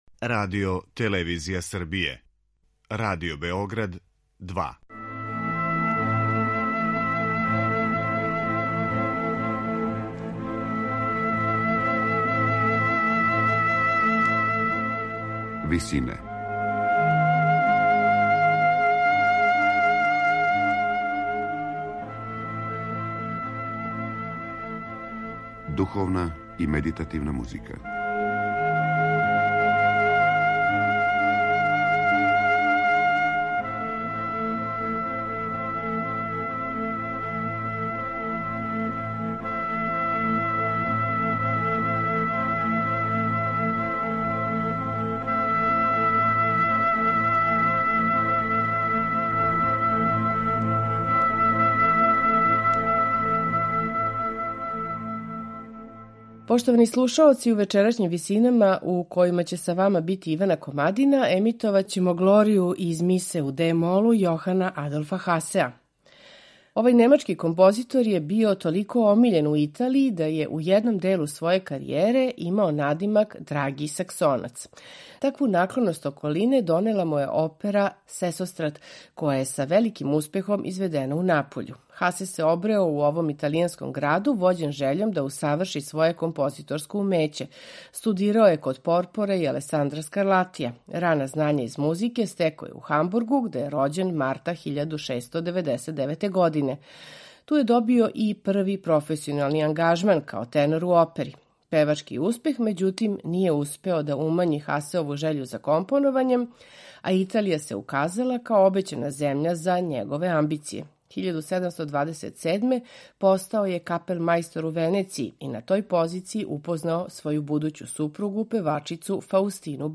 Глорија из ове мисе сматрана је најбољим примером барокне католичке црквене музике. Као и сва своја друга црквена дела, Хасе је ту мису компоновао за извођачки састав који му је био на располагању: нестандардан састав оркестра (флауте, трубе, хорне и бубњеви) и дечји хор, који је у Дрезден доведен из католичке Чешке.
сопран
тенор